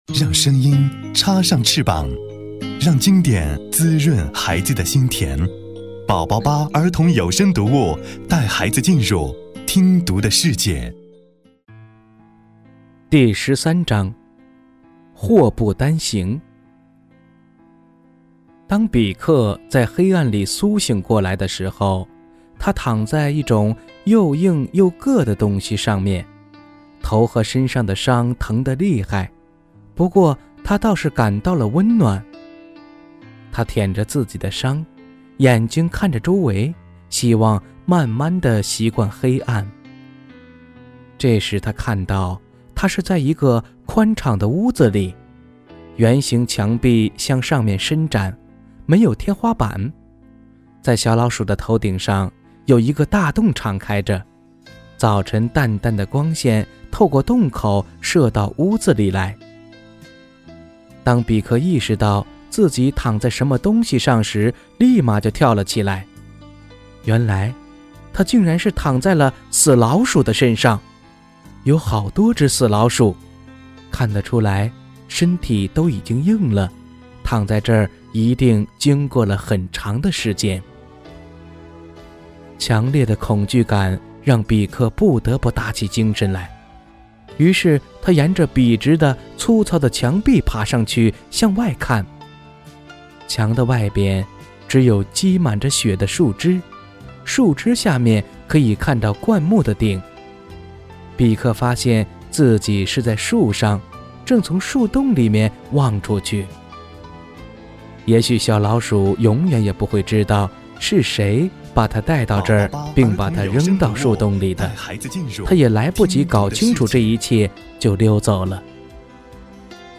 首页>mp3 > 儿童故事 > 13祸不单行(小老鼠比克流浪记)